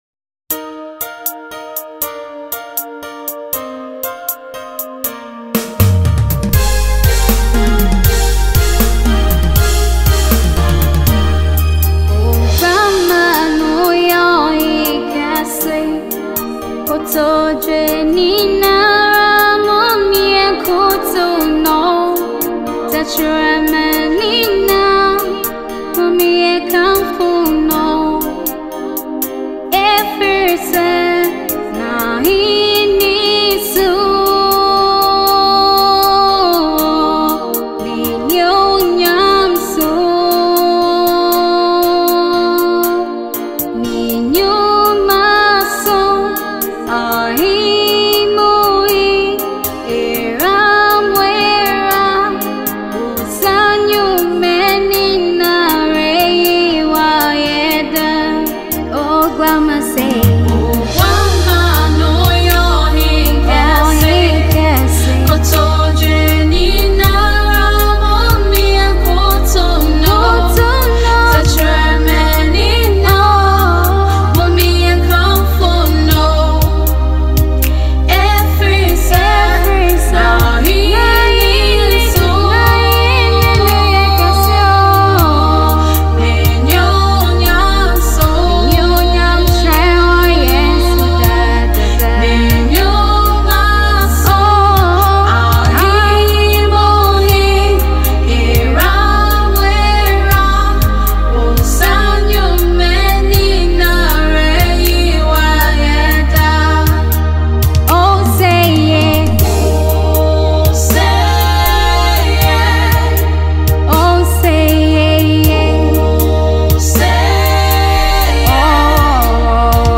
Dubbed Ghanaian singer
new music that inspires to worship and uplifts